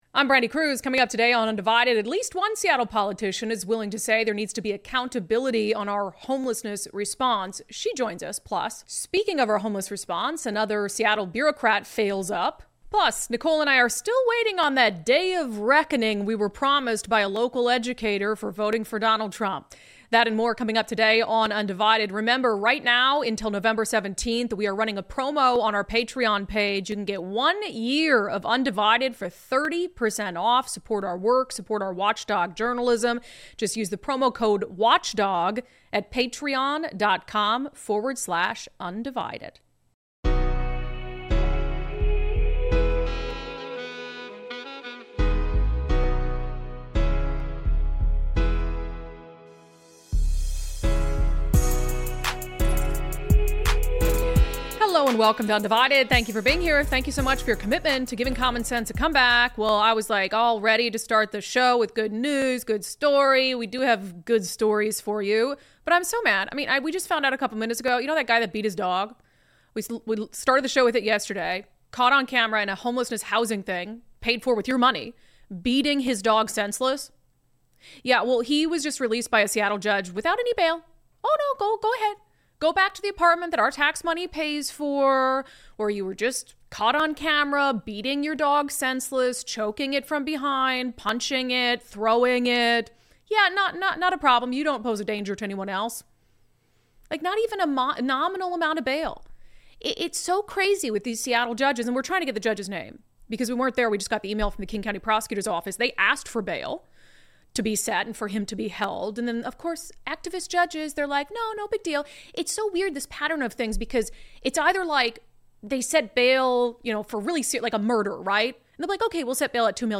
Seattle City Councilwoman Joy Hollingsworth joins us to discuss need for accountability on regional homeless response. Another Seattle bureaucrat fails up. Democrats should be very worried about losing Asian voters.